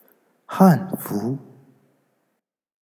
Hanfu_pronunciation.ogg